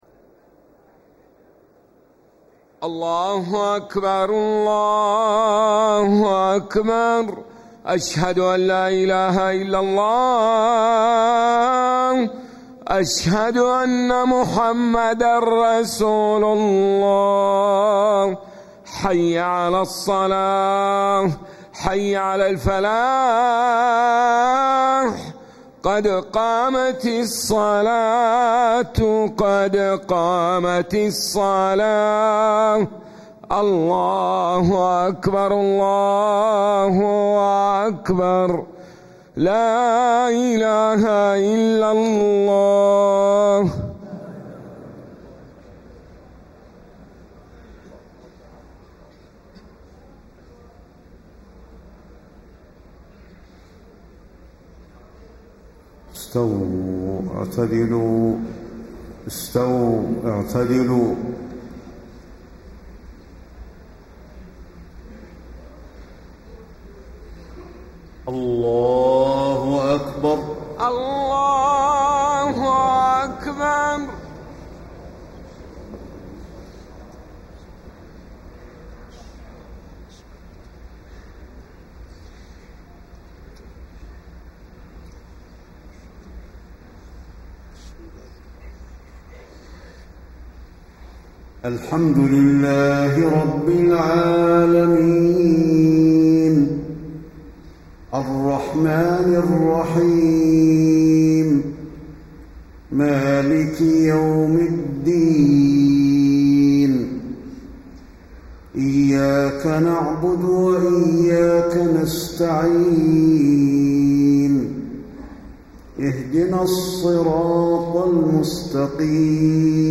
صلاة المغرب 7-7-1434هـ سورتي الزلزلة و الكافرون > 1434 🕌 > الفروض - تلاوات الحرمين